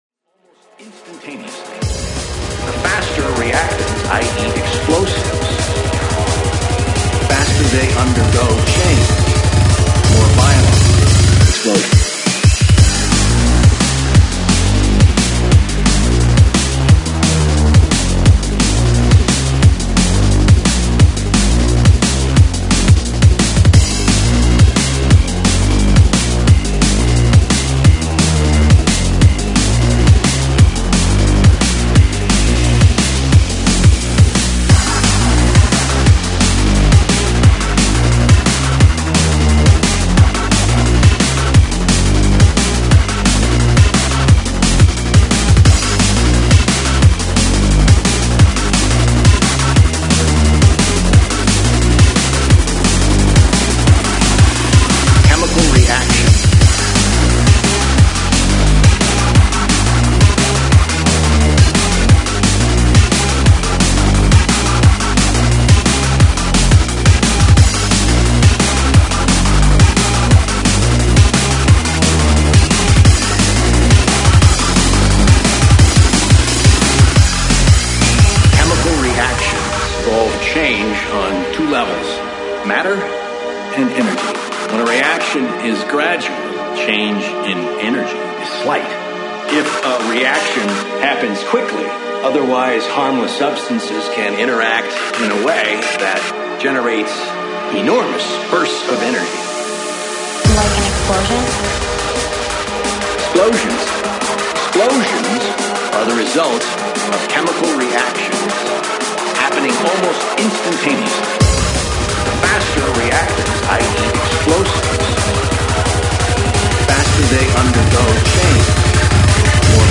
Freeform